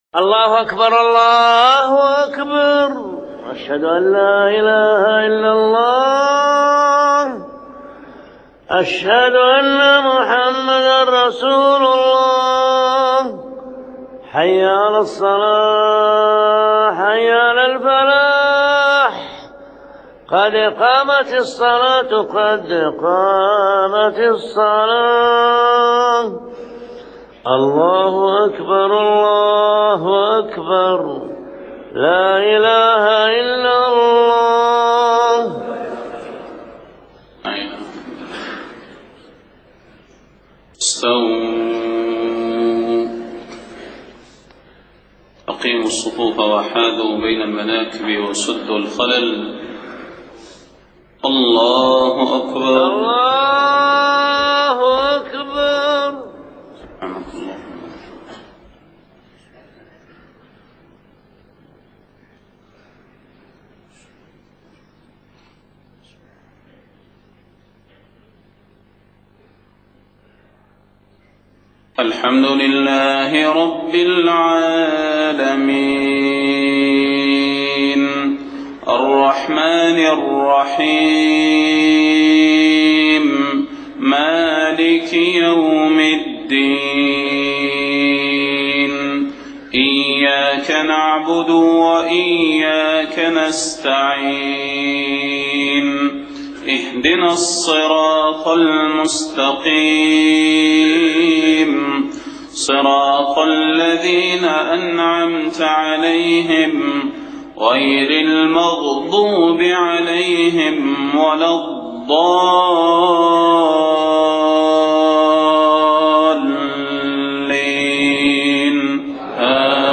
صلاة الفجر 15 محرم 1430هـ سورة الإنسان كاملة > 1430 🕌 > الفروض - تلاوات الحرمين